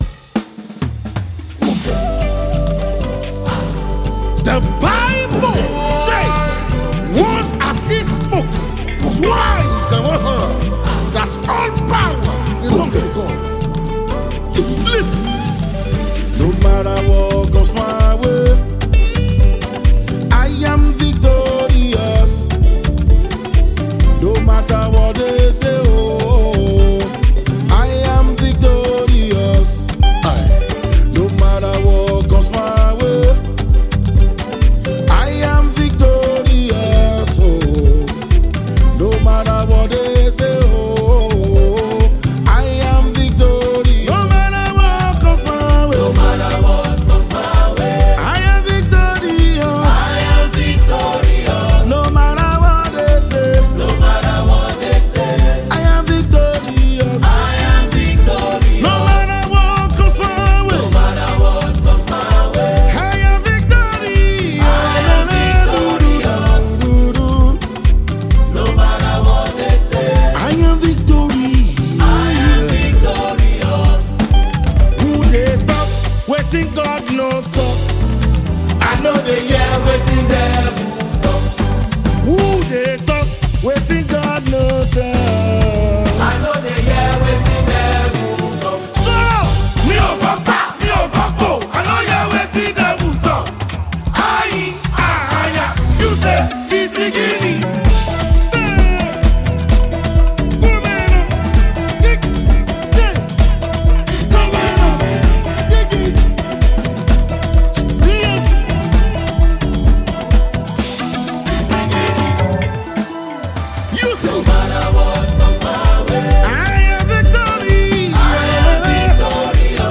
an acclaimed Nigerian gospel artist and worship leader
soulful song